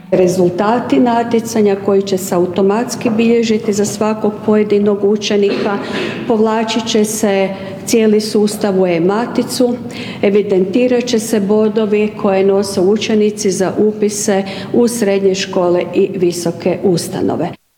Planirano je ove škoslke godine da se održi 38 natjecanja, a ako se zbog pandemije ne mognu održati uživo, planira se i online opcija, rekla je ravnateljica Agencije Dubravka Brezak Stamać: